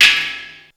Ride.wav